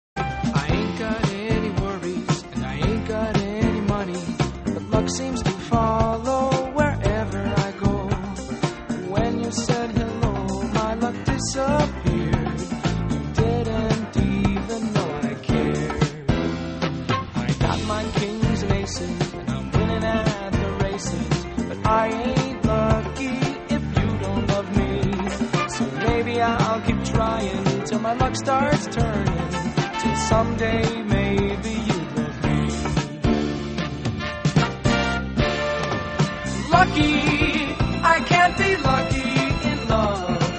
trés frais , j'adore.